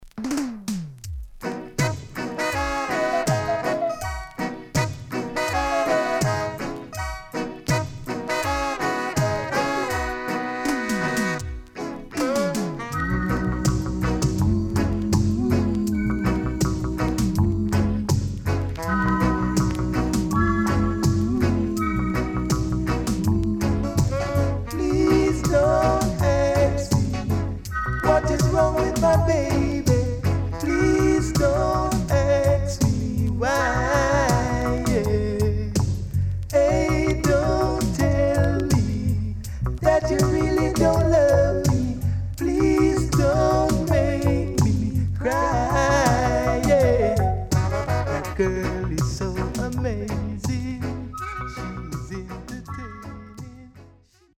HOME > DISCO45 [DANCEHALL]  >  EARLY 80’s
Sweet Tune
SIDE A:うすいこまかい傷ありますがノイズあまり目立ちません。